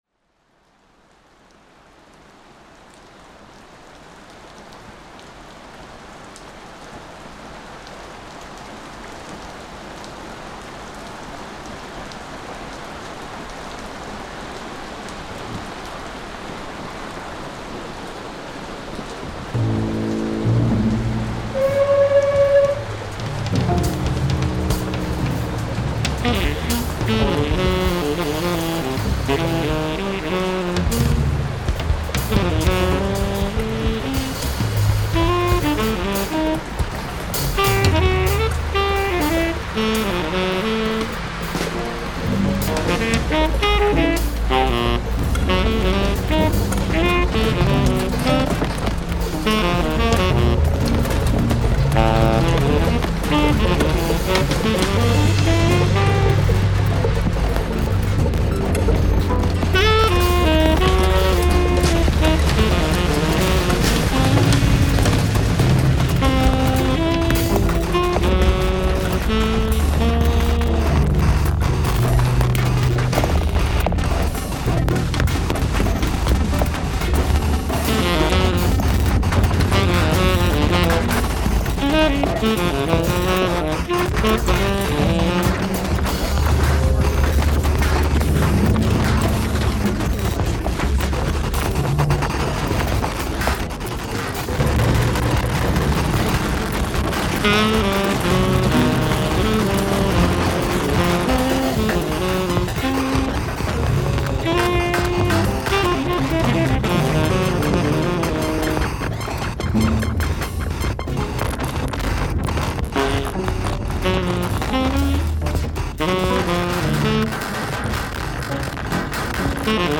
Live Music, Saxophone